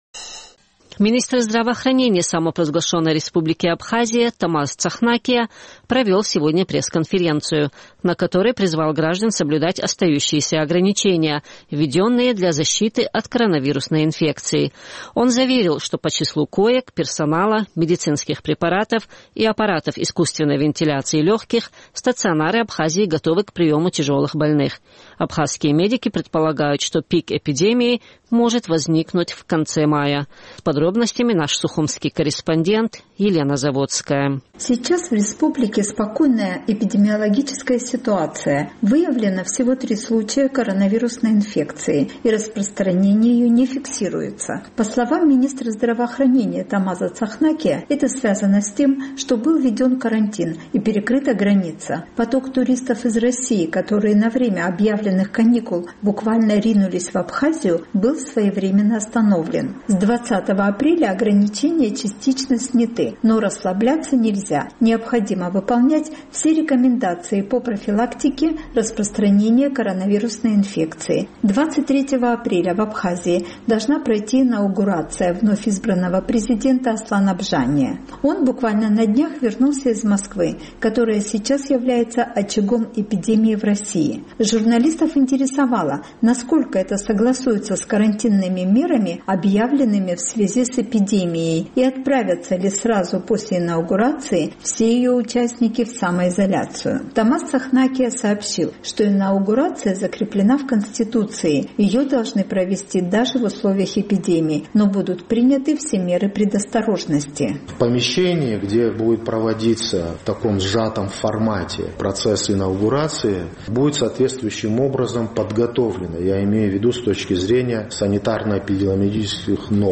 Министр здравоохранения Абхазии Тамаз Цахнакия провел сегодня пресс-конференцию, на которой призвал граждан соблюдать остающиеся ограничения, введенные для защиты от коронавирусной инфекции.